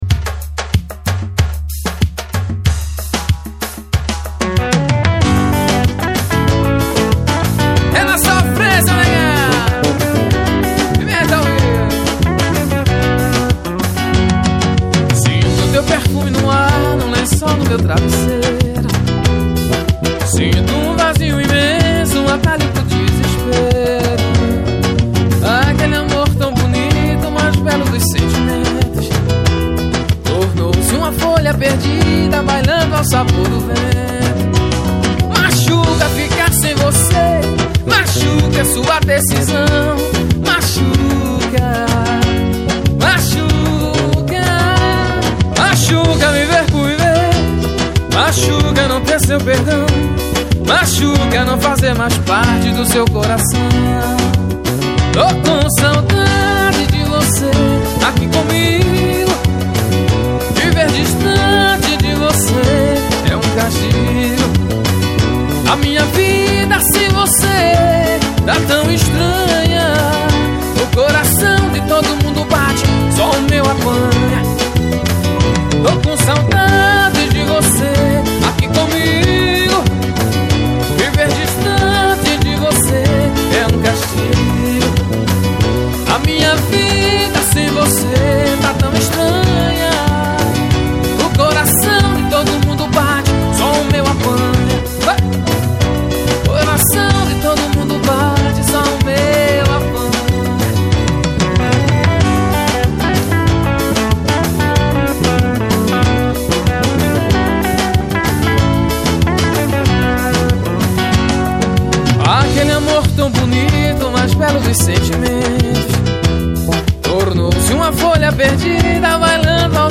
Ao vivo 2015.